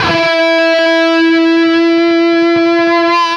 LEAD E 3 CUT.wav